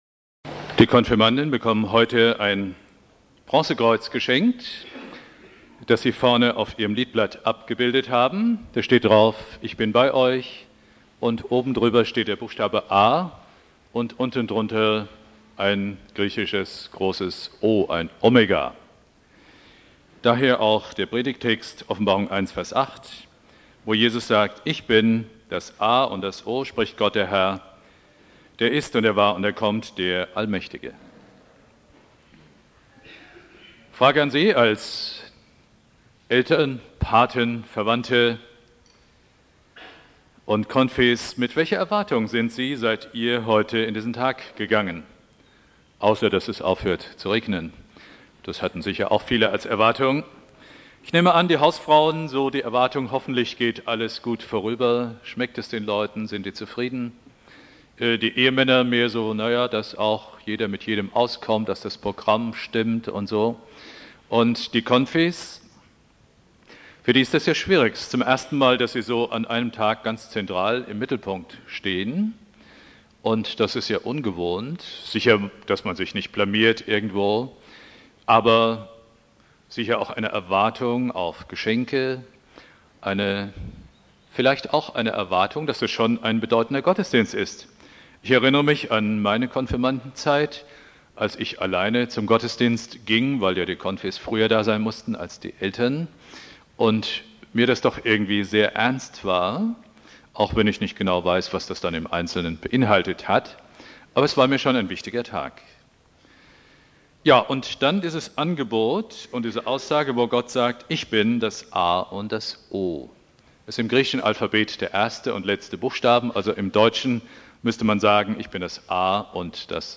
Predigt
Thema: "Ich bin das A und das O" (Konfirmation Obertshausen) Bibeltext